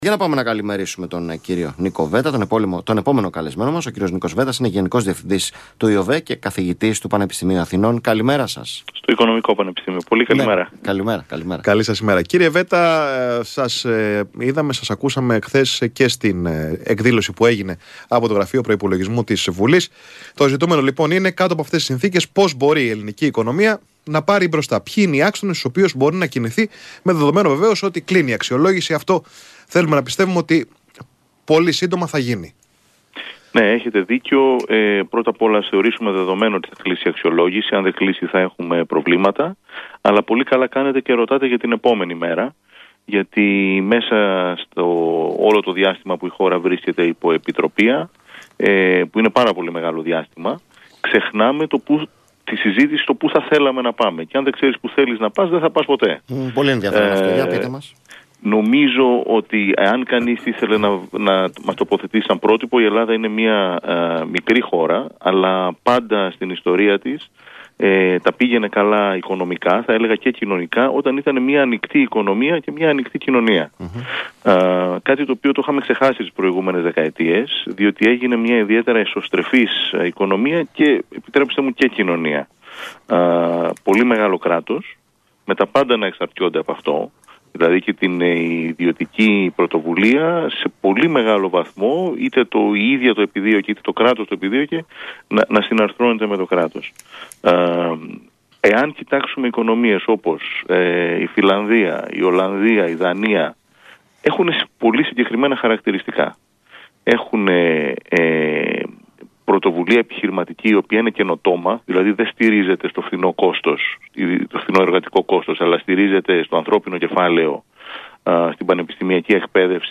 Συνέντευξη στο ραδιοφωνικό σταθμό Στο Κόκκινο 105,5 FM